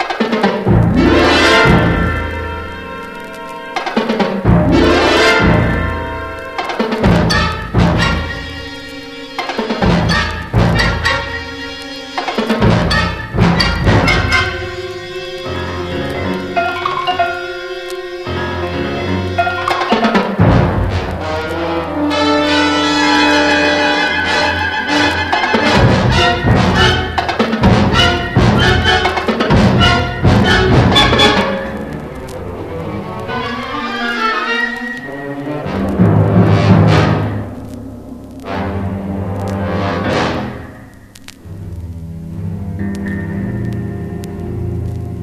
幻のサイケ・ガレージ・サントラ！